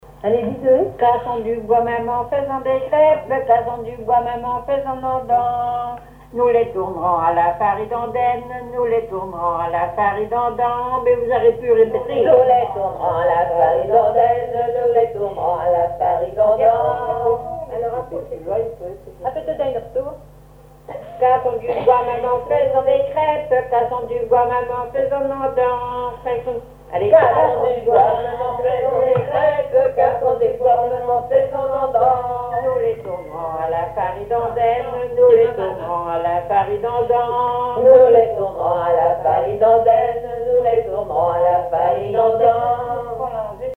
branle
Couplets à danser
collecte en Vendée
Veillée de chansons
Pièce musicale inédite